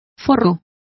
Complete with pronunciation of the translation of liners.